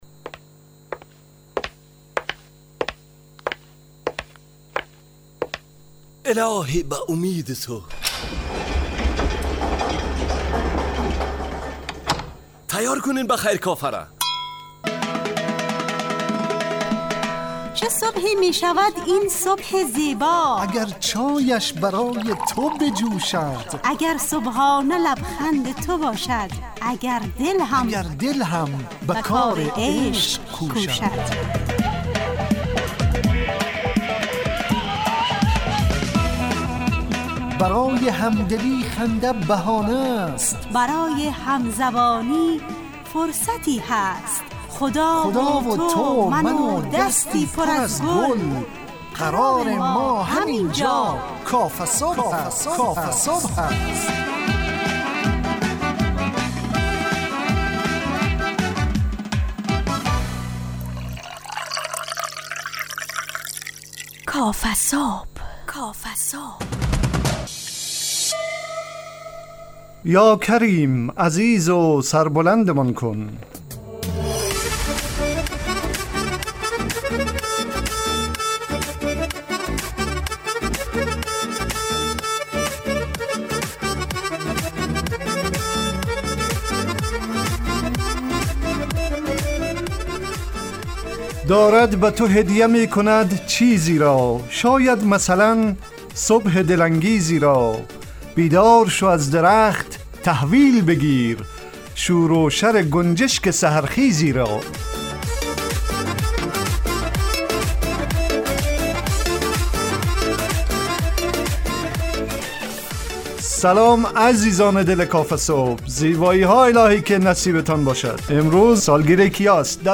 کافه‌صبح – مجله‌ی صبحگاهی رادیو دری